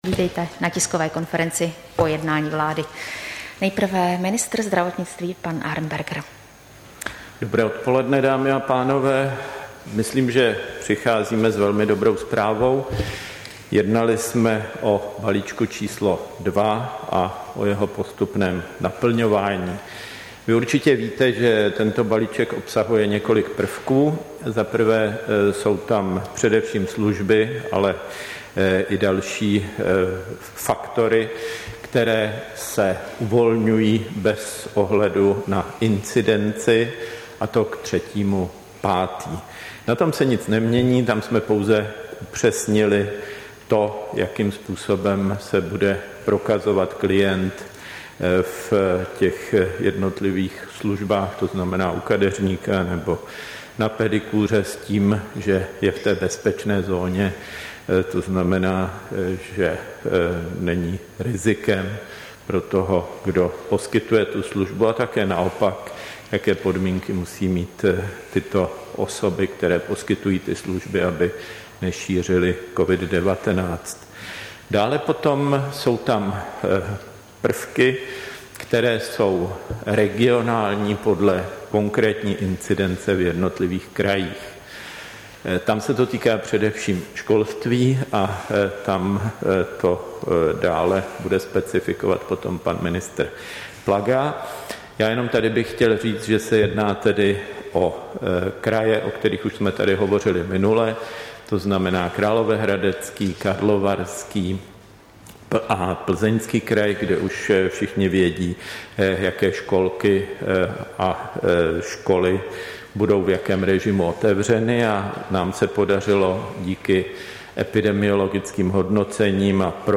Tisková konference po mimořádném jednání vlády, 29. dubna 2021